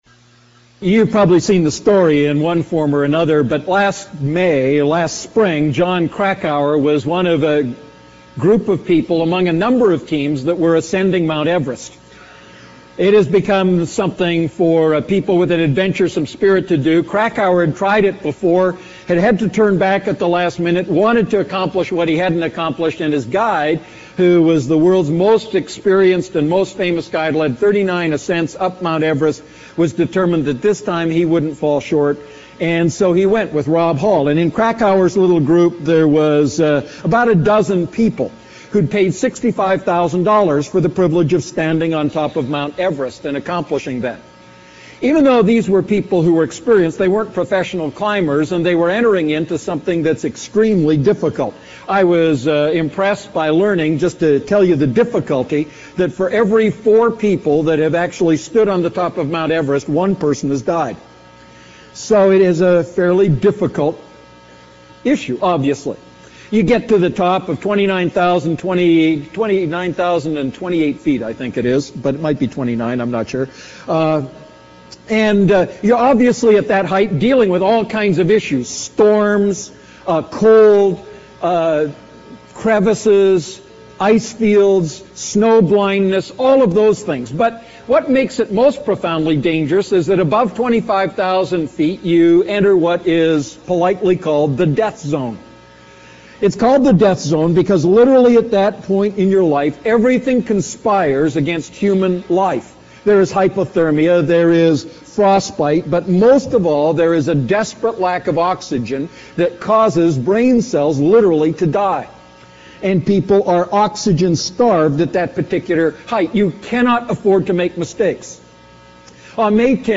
A message from the series "Holy Spirit."